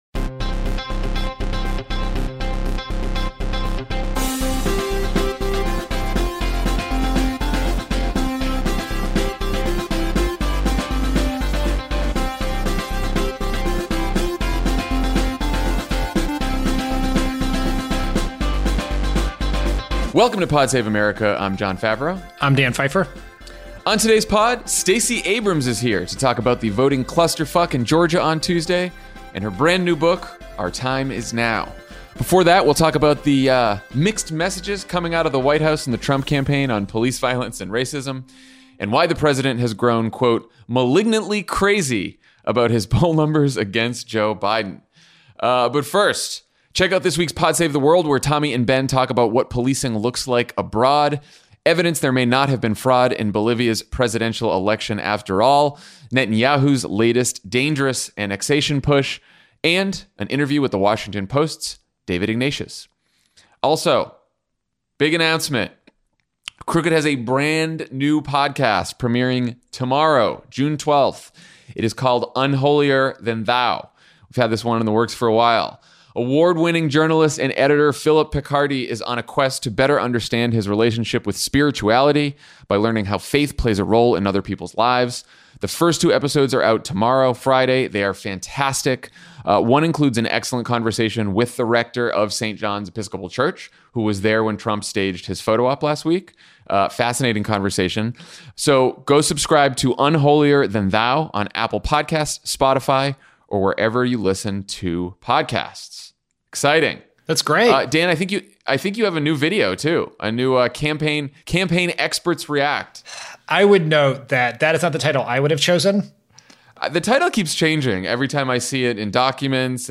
Trump prepares for a potential speech on race and unity by attacking an elderly protester and defending Confederate-named buildings, Republicans feel the pressure on police reform, and Joe Biden opens up a big lead in the polls. Then Stacey Abrams talks to Jon about the voting disaster in Georgia and her new book, Our Time Is Now.